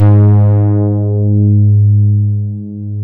MODULAR G#3M.wav